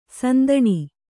♪ sandaṇi